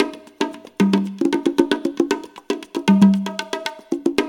CONGA BEAT33.wav